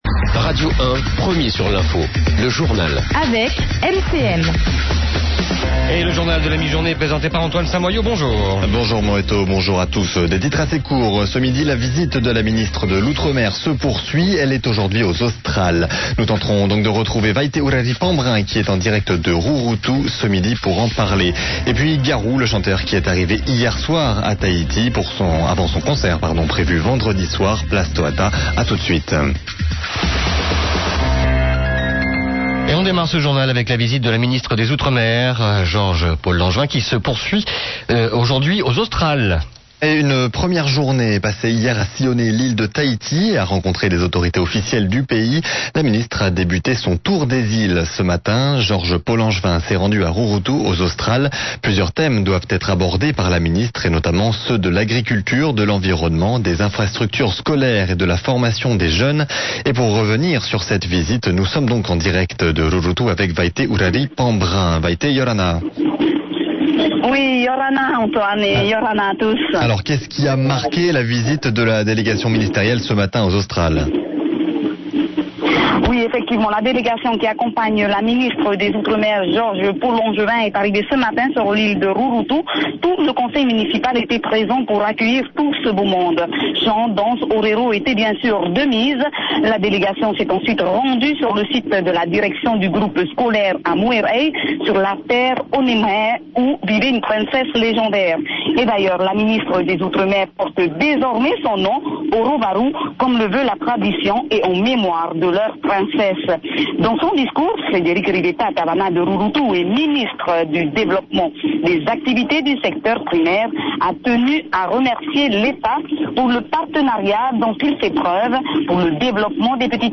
Journal de 12:00, le 10/03/2015